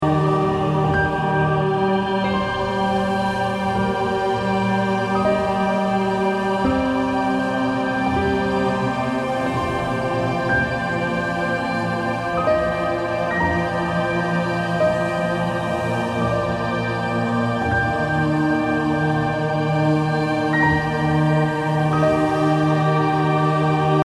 528hz Ambient calm Healing Instrument インストルメント 癒し 穏やか
BPM 80